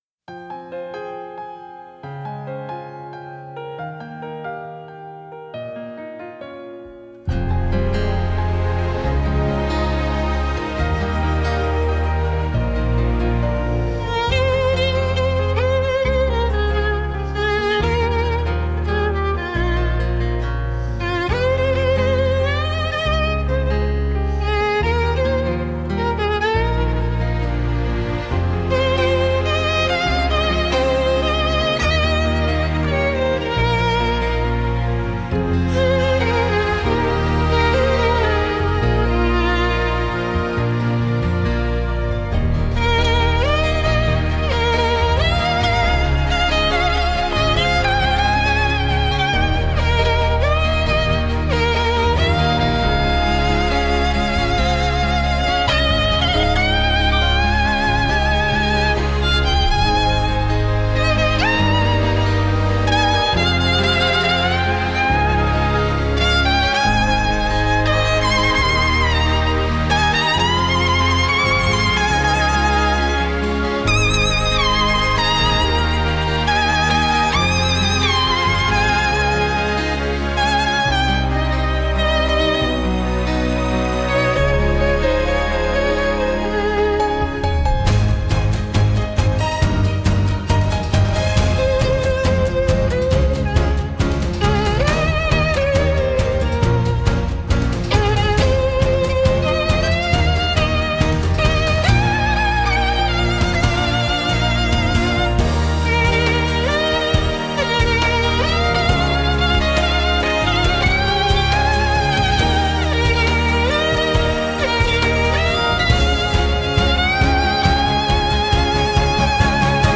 Genre: Score